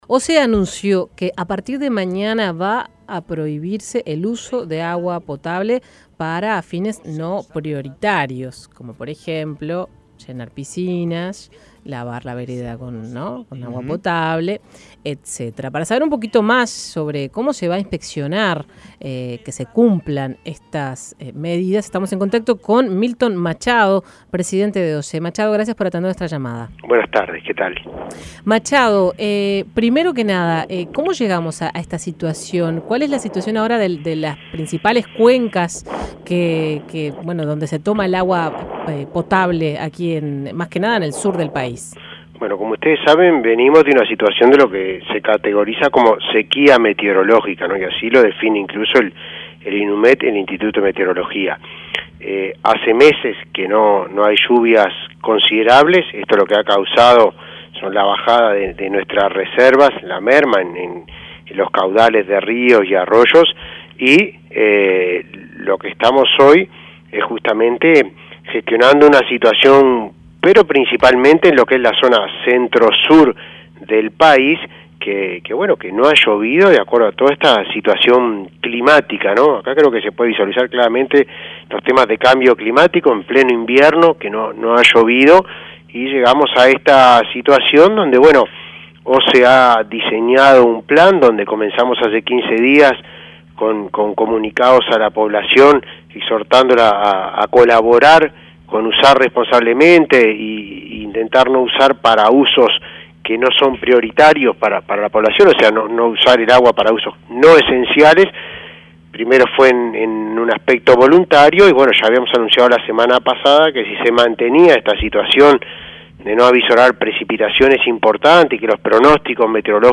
Escuche la entrevista a Machado
El presidente de OSE, Miltón Machado, dijo a 810 Vivo que van a controlar, monitorear y recorrer para que la medida se acate y que esto estará a cargo de las personas que trabajan en el área comercial operativa, el cuerpo de inspectores para temas de fraude y se va disponer de personal especialmente para la ocasión.